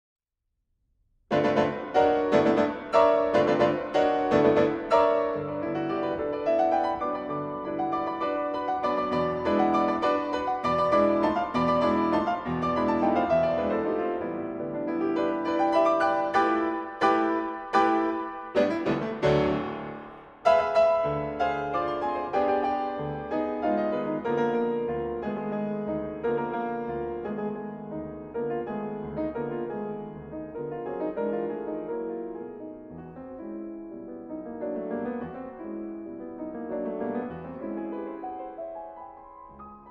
piyano